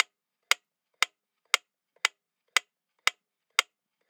Metronome Medium.wav